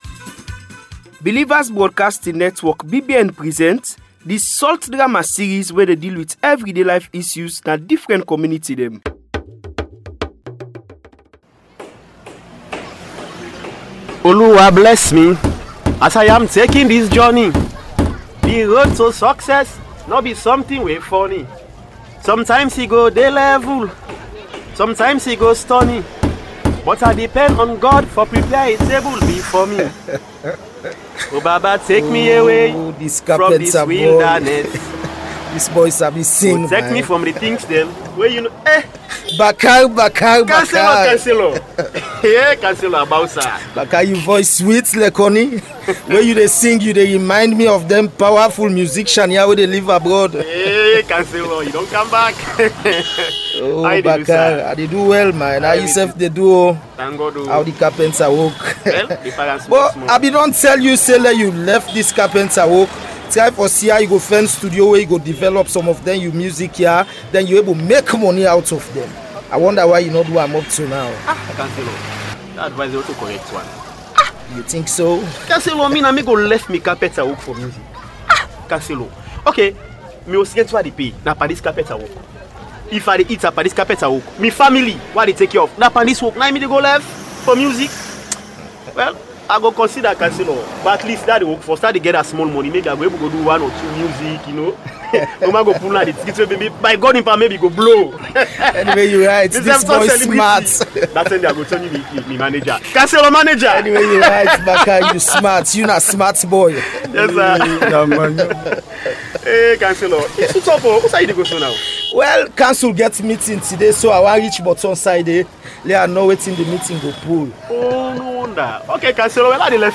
Radio drama in Sierra Leone
Bakar is working on refurbishing a set of chairs and singing a nice song.
As he leaves, Gibo arrives looking and sounding as if he is high on drugs.